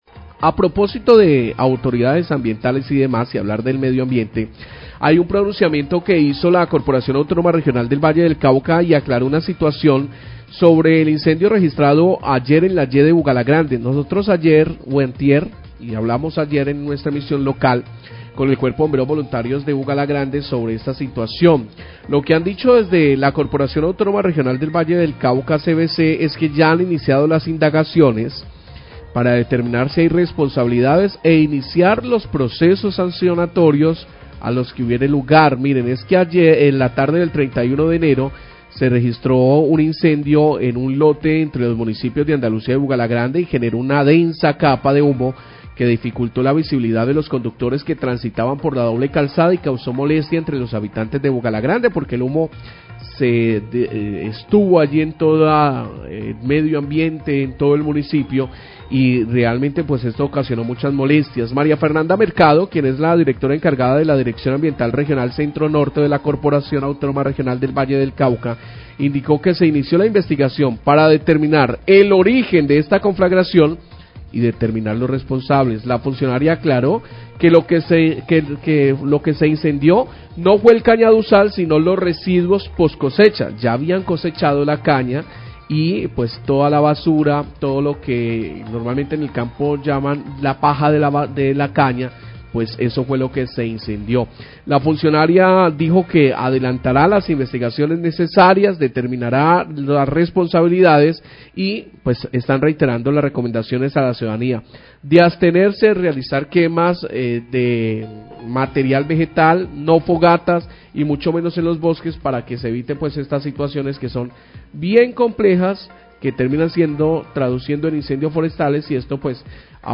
Radio
pronunciamiento